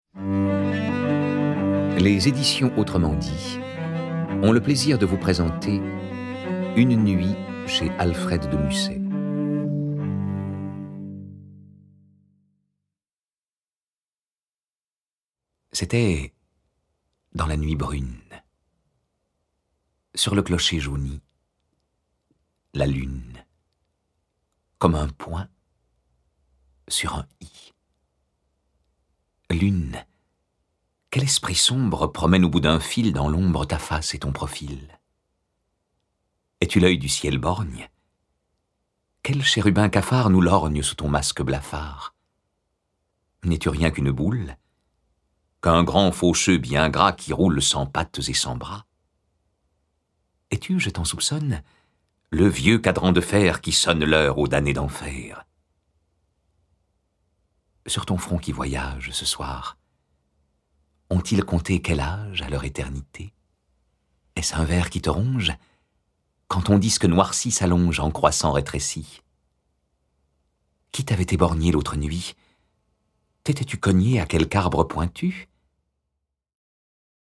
C’est à un parcours poétique à travers toute la création d’Alfred de Musset ( 1810-1857 ) que nous convie la voix chaude